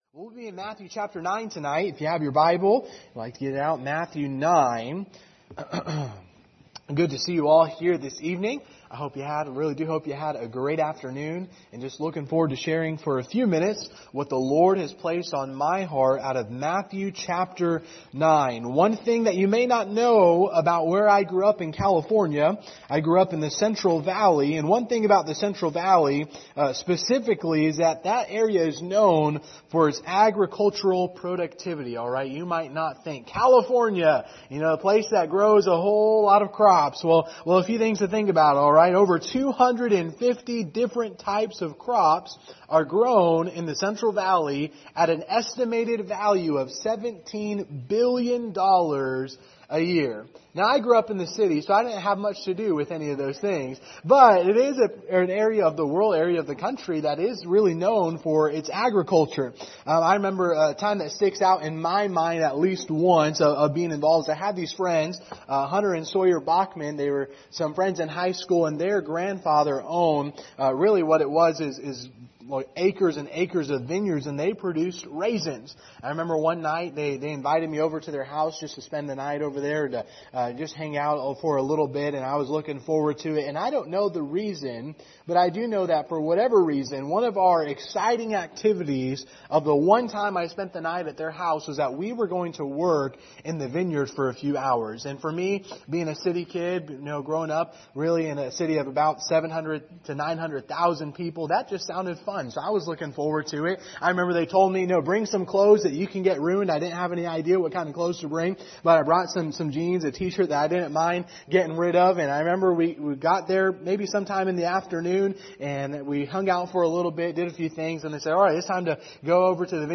Service Type: Sunday Evening Topics: evangelism , gospel